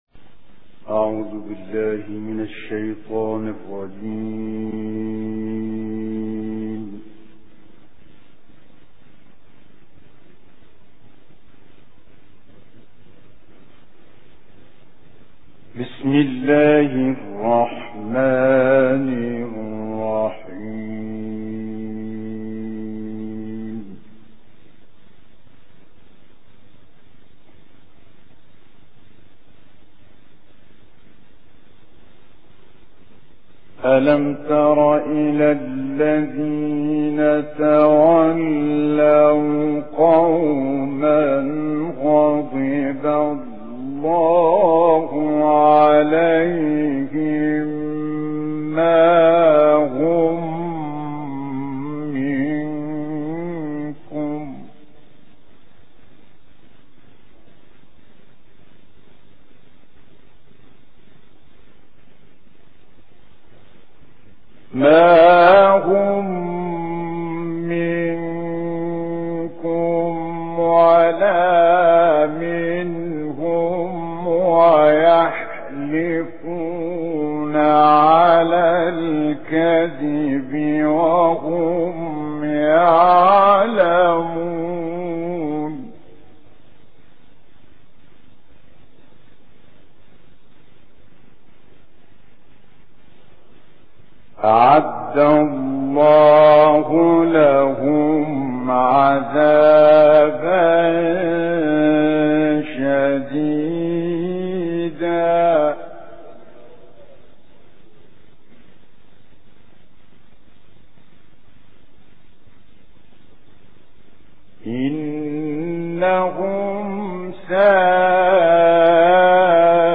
Recitations